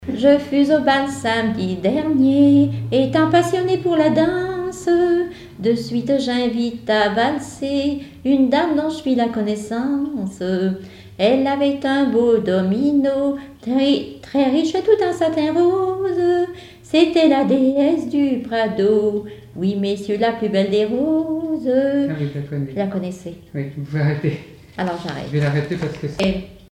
Genre strophique
chansons et témoignages parlés
Catégorie Pièce musicale inédite